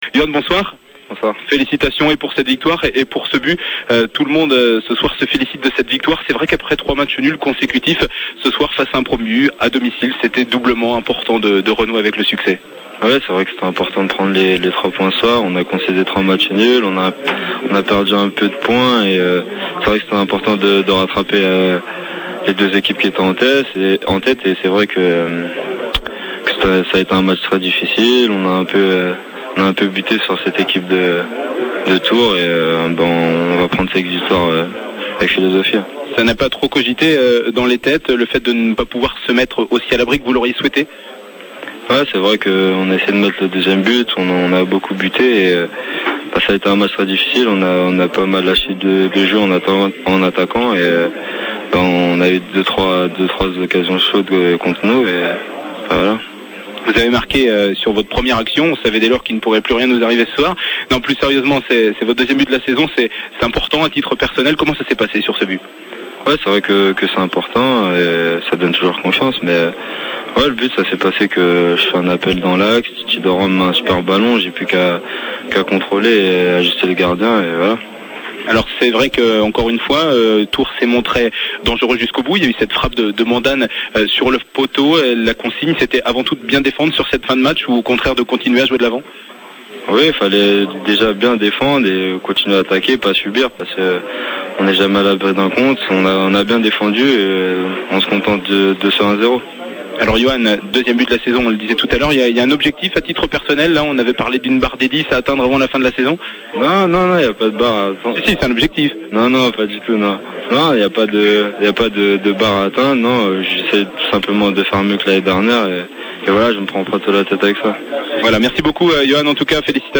interview complète